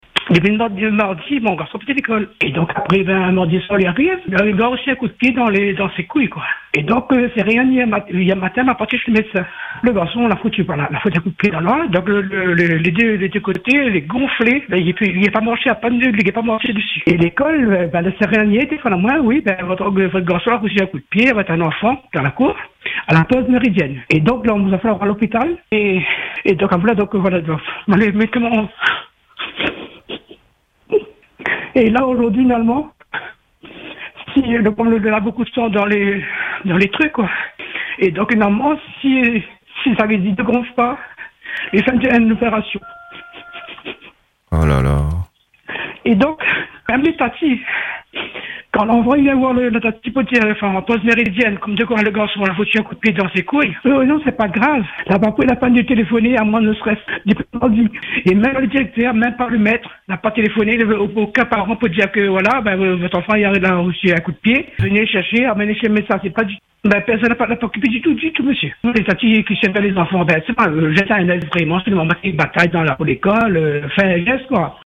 Une mère profondément bouleversée a partagé son récit poignant aujourd’hui sur les ondes de Radio Freedom.